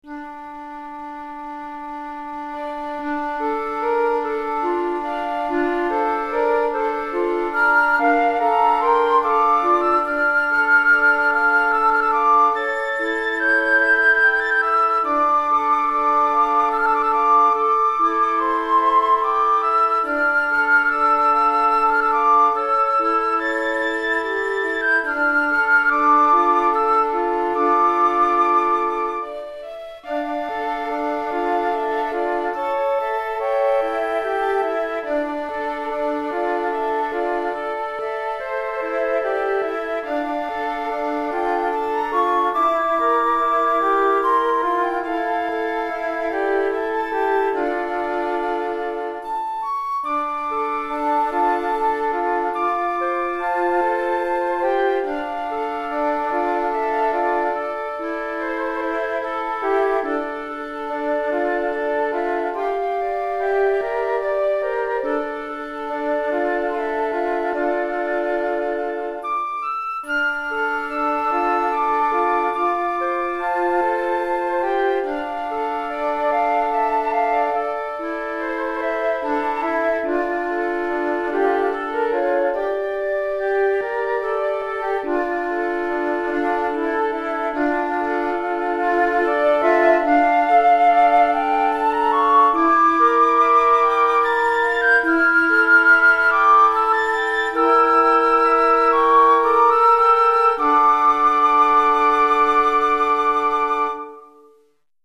4 Flûtes Traversières